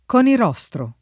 [ k q nir 0S tro ]